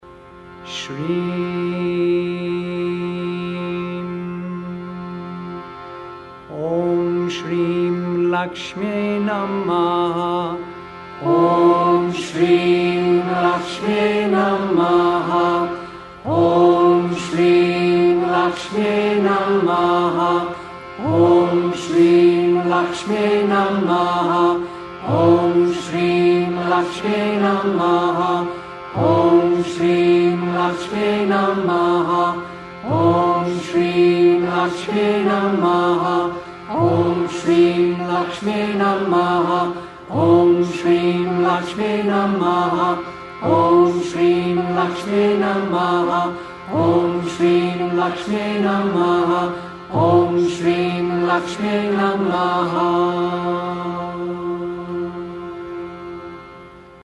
Laksmi, bija mantra
Laksmi-bija-mantra.mp3